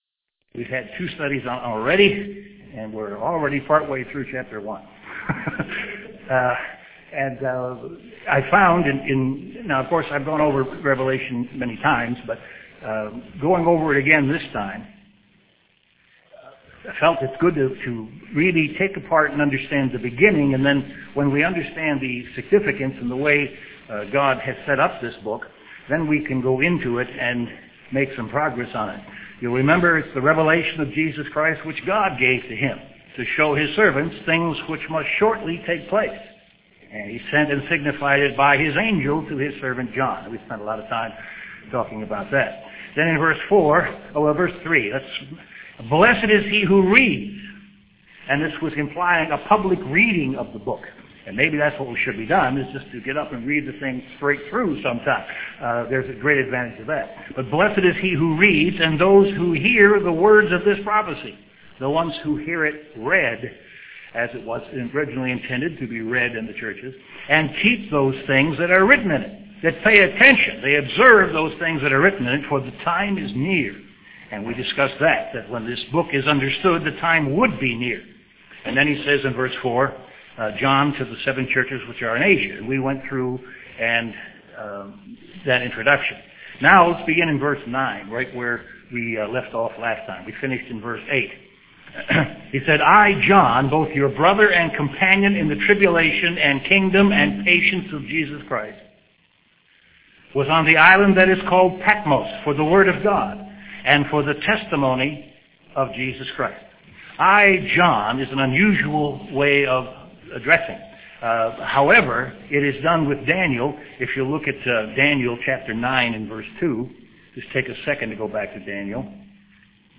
Given in Chicago, IL
UCG Sermon